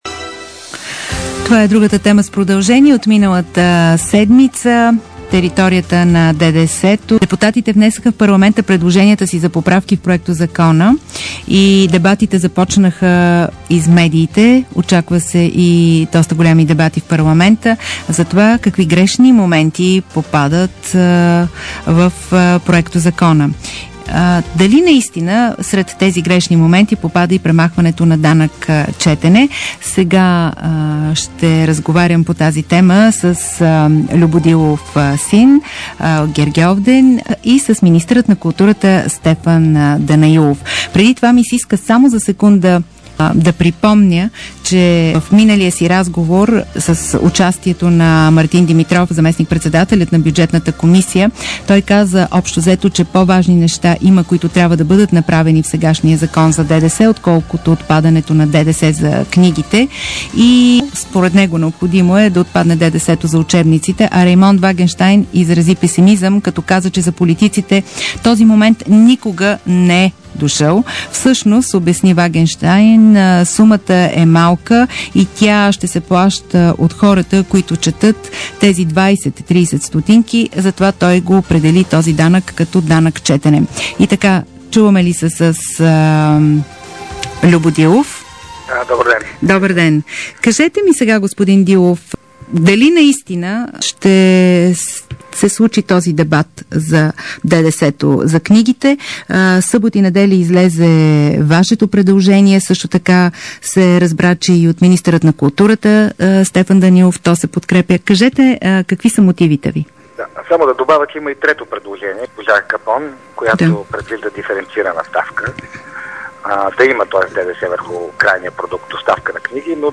DarikNews audio: Интервю